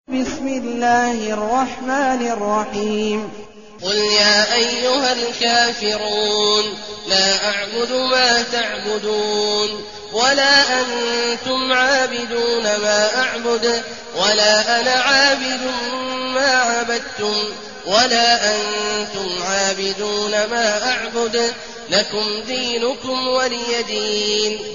المكان: المسجد الحرام الشيخ: عبد الله عواد الجهني عبد الله عواد الجهني الكافرون